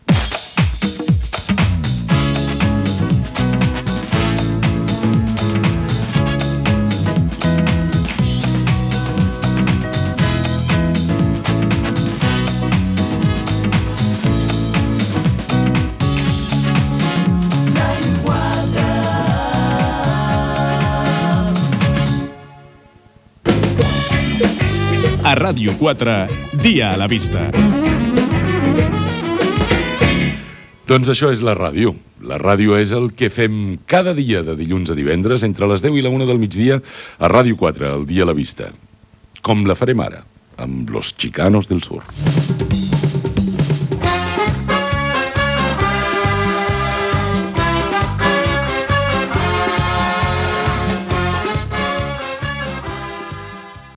Indicatiu de l'emissora i del programa i tema musical.